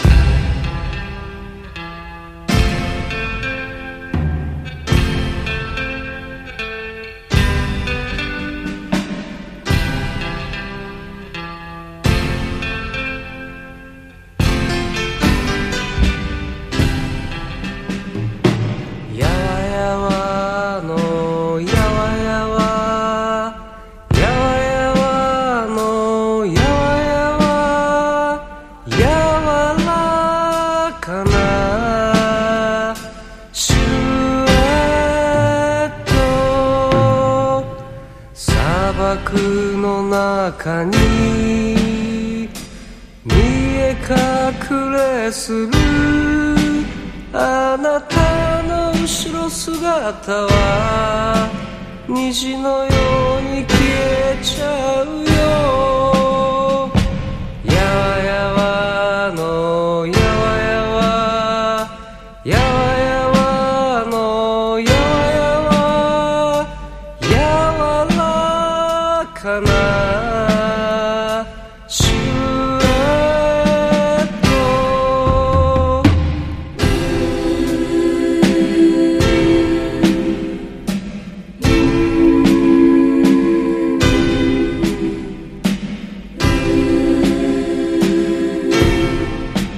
NO WAVE / POST PUNK、東京ロッカーズ、あぶらだこ、ゆらゆら帝国好きまで必聴！